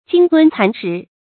鯨吞蠶食 注音： ㄐㄧㄥ ㄊㄨㄣ ㄘㄢˊ ㄕㄧˊ 讀音讀法： 意思解釋： 像鯨魚吞食一口吞下，像蠶吃桑葉逐步侵占。